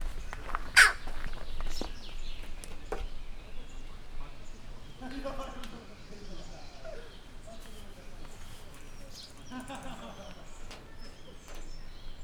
marismeroskentudvozolminket_miskolczoo0012.WAV